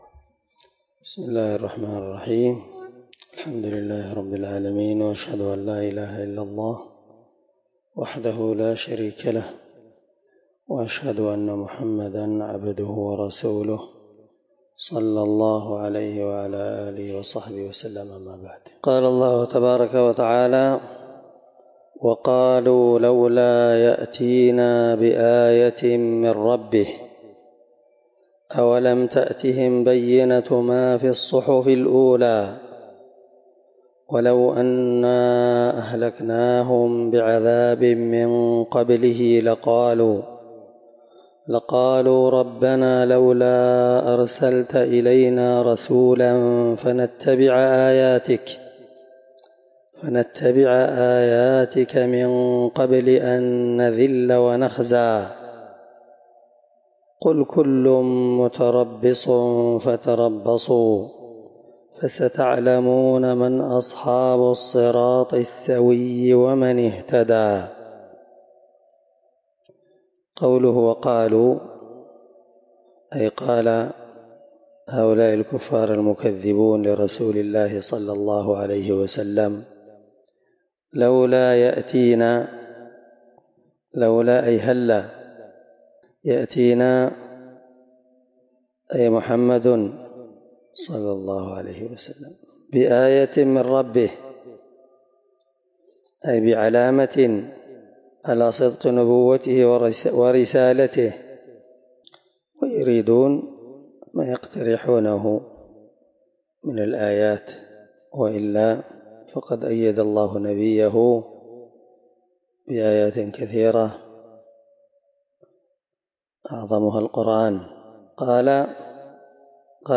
الدرس28تفسير آية (133-135) من سورة طه